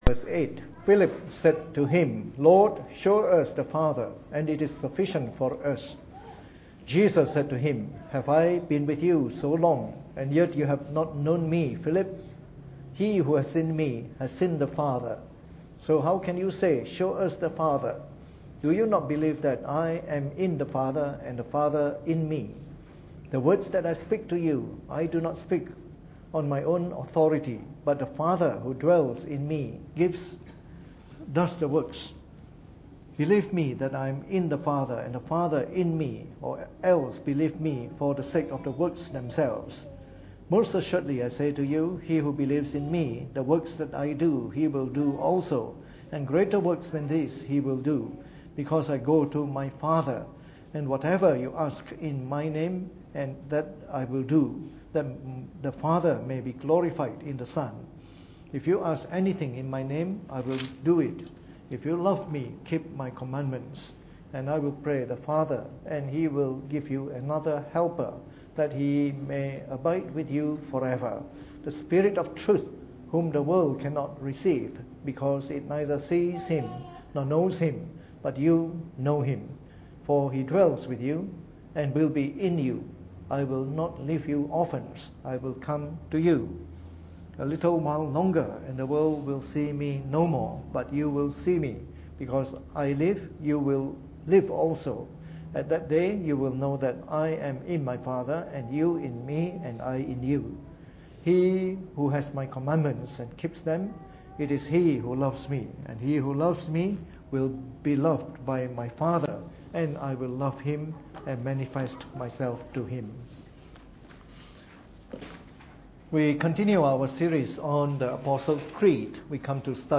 Preached on the 8th of February 2017 during the Bible Study, from our series on the Apostles’ Creed.